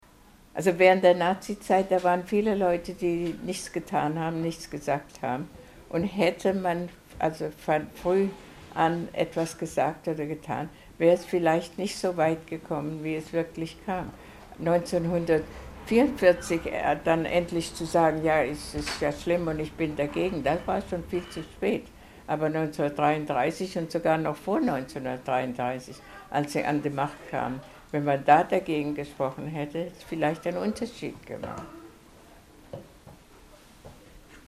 Die Gelegenheit, der Rednerin nach ihren Ausführungen noch Fragen zu stellen, wurde von den Jugendlichen ausgiebig in Anspruch genommen.